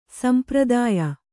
♪ sampradāya